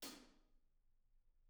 R_B Hi-Hat 02 - Room.wav